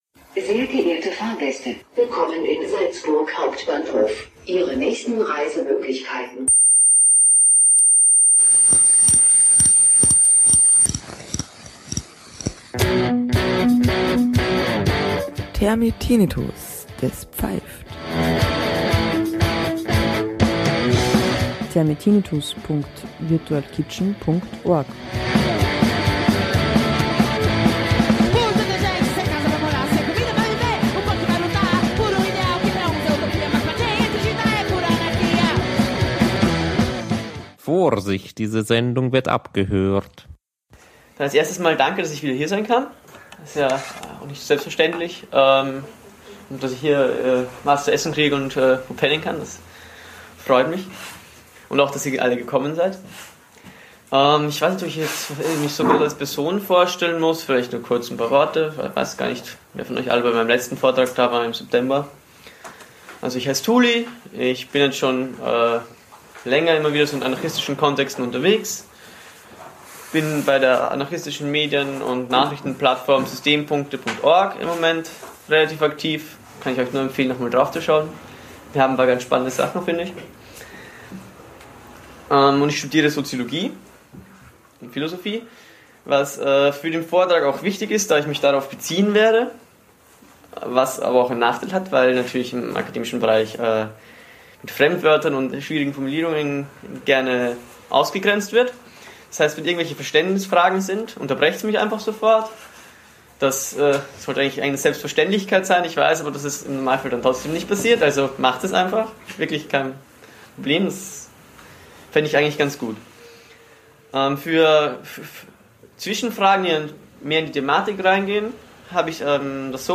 Vortrag: Staat und Gewalt
Die Diskussion wurde aus dem Beitrag herausgeschnitten.